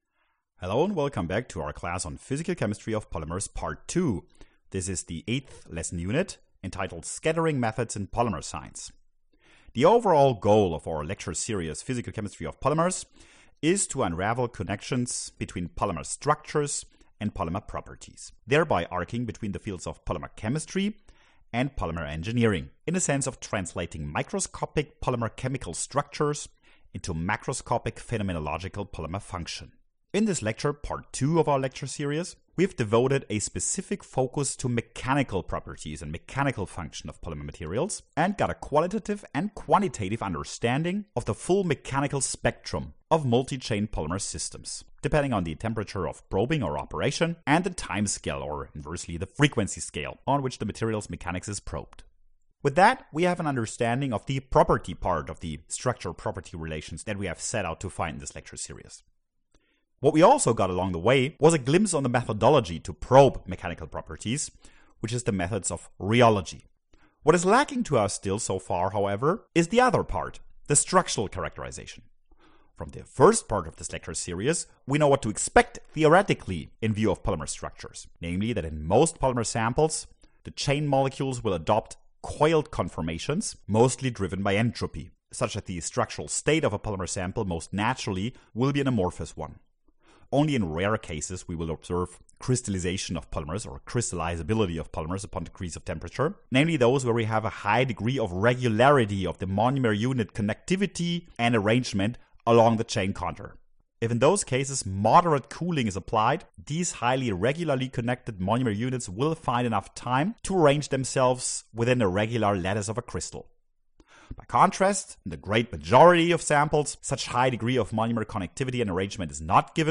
Lesson8-Scattering-Methods-in-Polymer-Science_low.mp3